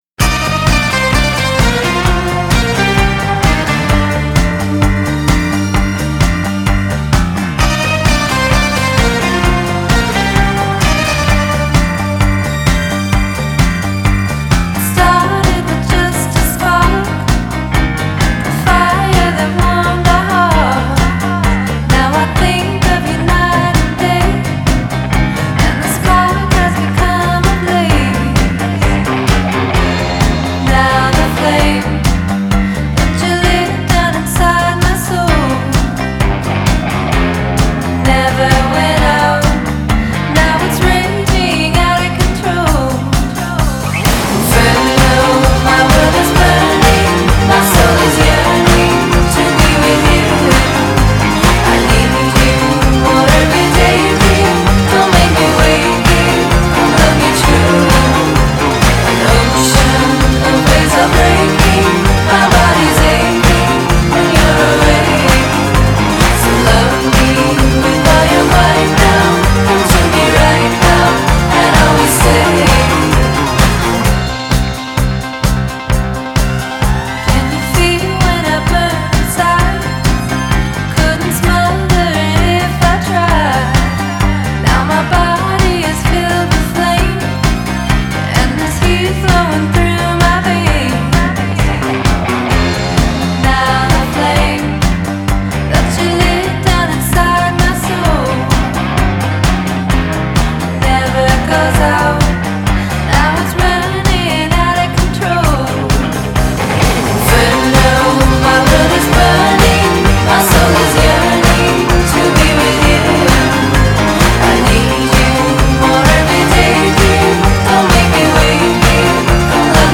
Genre: Indie Pop, Synthpop, Female Vocal